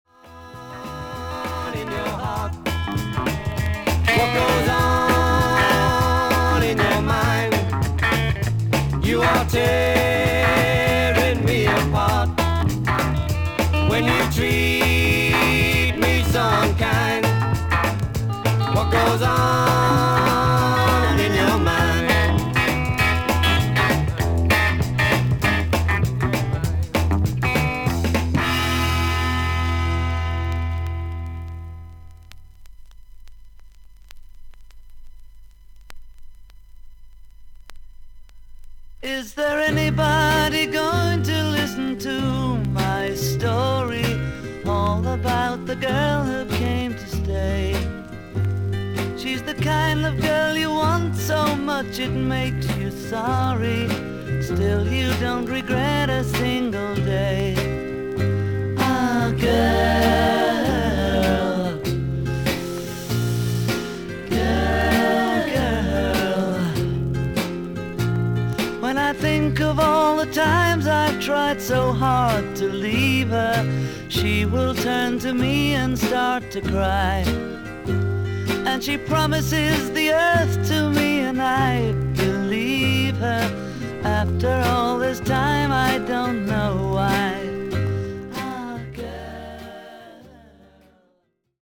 最初のマトリックスでラウド・カットです。
試聴はキズがあるB1後半からB2です。